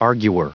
Prononciation du mot arguer en anglais (fichier audio)
arguer.wav